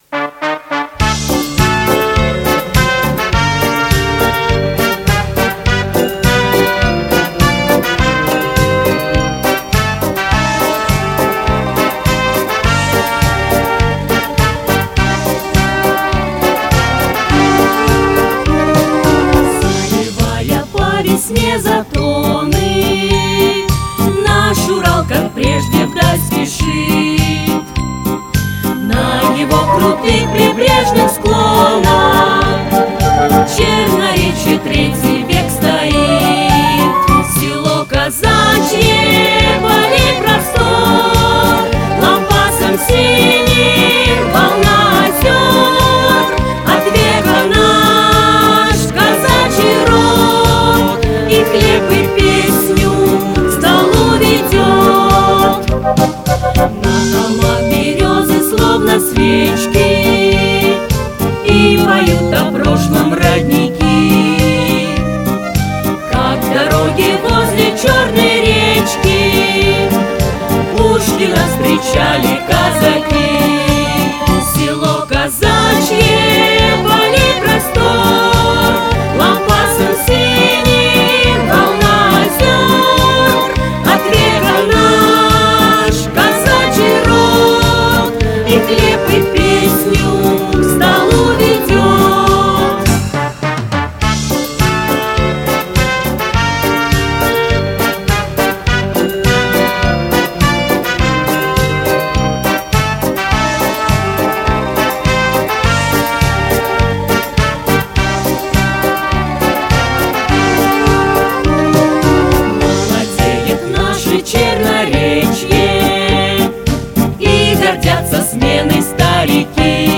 Музыка Алексея Цибизова
Слова Геннадия Коняхина